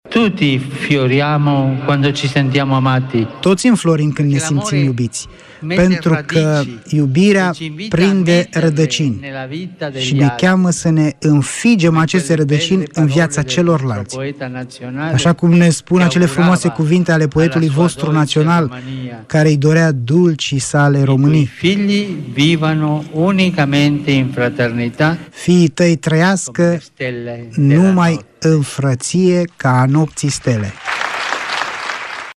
Sfântul Părinte a salutat mulţimea în limba română, de pe scena din faţa Palatului Culturii, unde a recitat şi versuri scrise de poetul Mihai Eminescu.